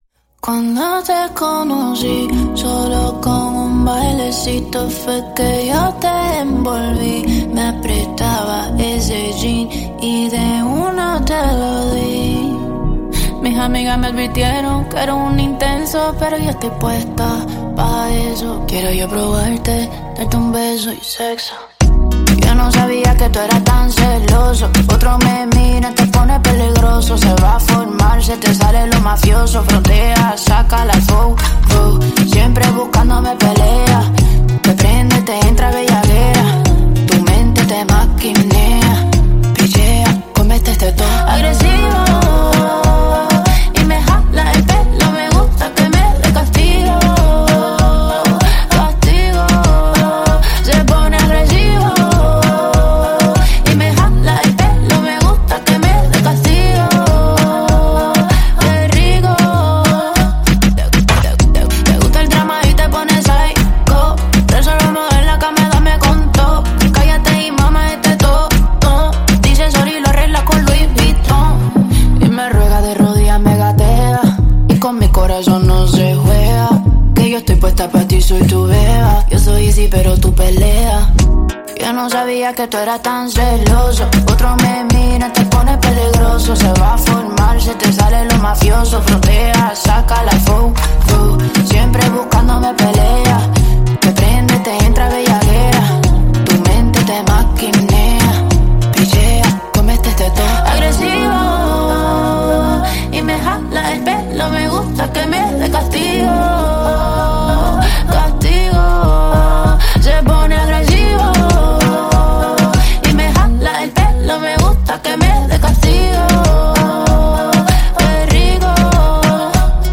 Жанр: Вокал